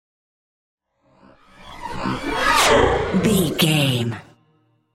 Sci fi vehicle pass by super fast
Sound Effects
futuristic
pass by
vehicle